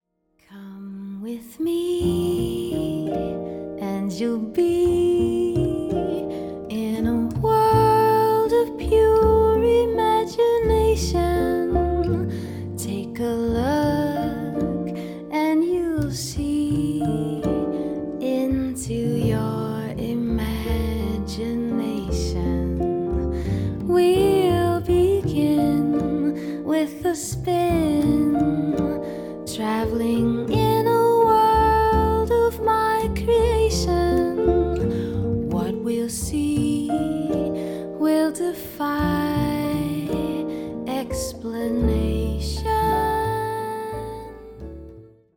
カナダ人ジャズ・シンガー